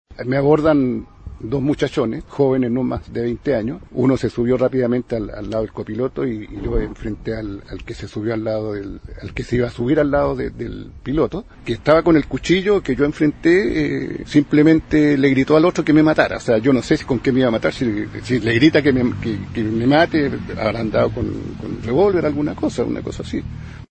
375-cuna-portonazo-victima.mp3